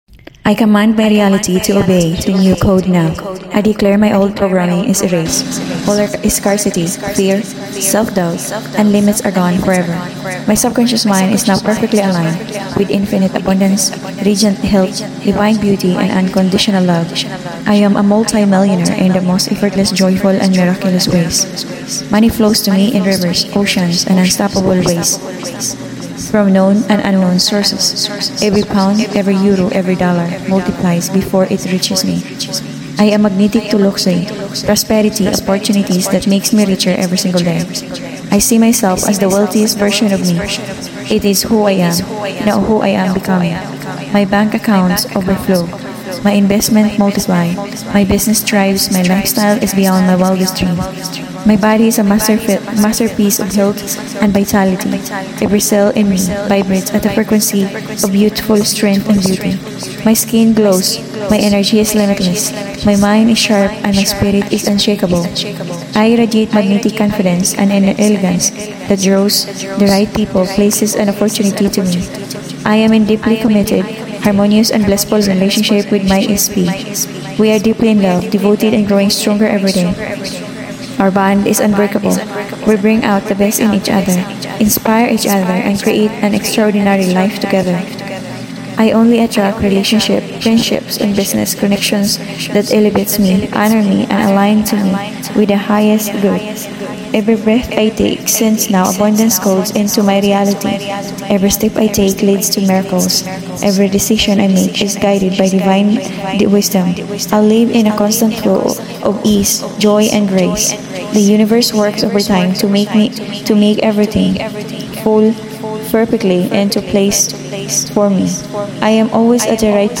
This will override your subconscious & rewrite your reality. Watch or listen daily in this rampage, repetition 888Hz, 777Hz & 528Hz miracle tones are layered and your holodeck shift into your millionaire, healthiest, most beautiful dream life version NOW.
Total Holodeck Rewrite Read slowly, with emotion.